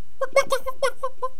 chicken_ack3.wav